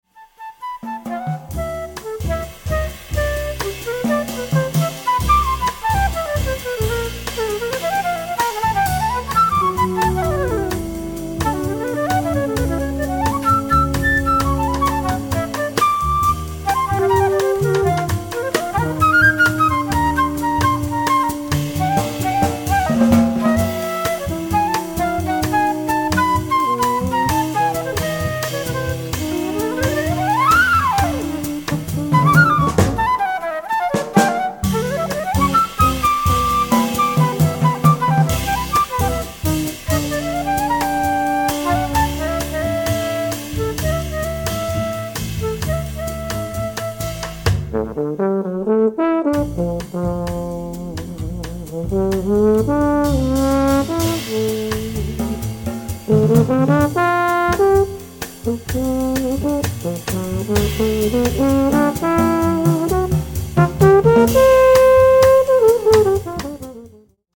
The Best In British Jazz
Recorded Doz Studios, Tunbridge Wells November 2008